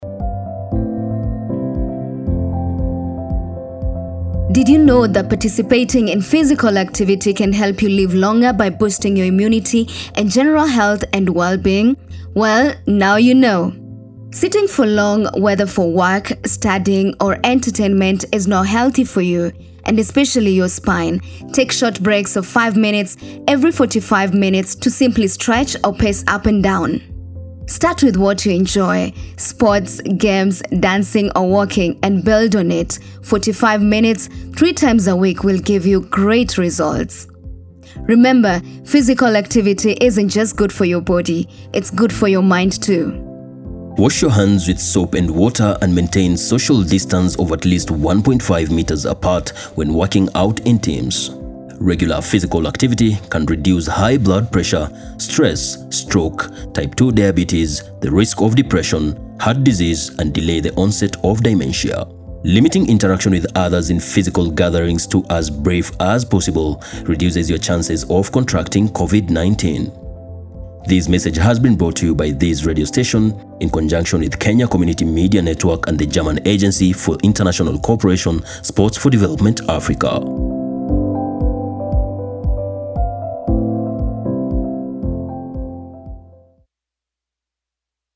Advantages of sports on human body – A Radio spot brought to you by KCOMNET in conjunction with Sports for Development Africa
ENGLISH-RADIO-SPOT-SPORT-AND-HEALTH-VO.mp3